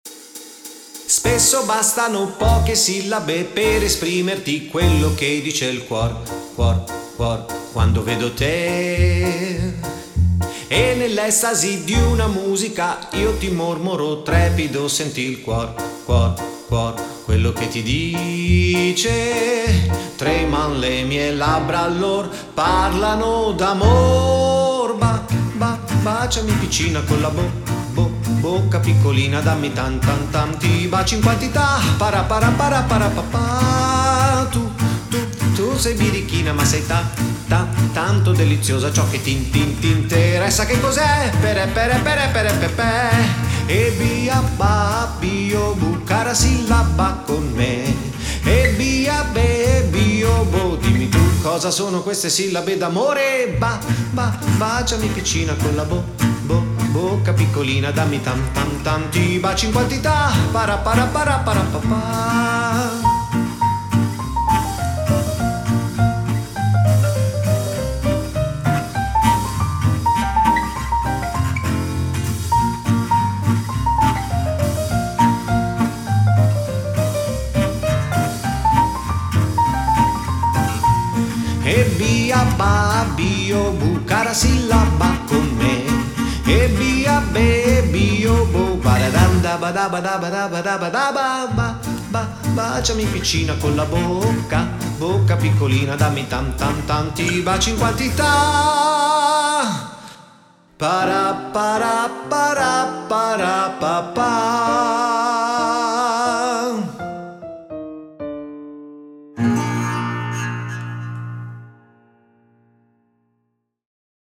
La voce femminile